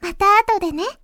pause-back-click.ogg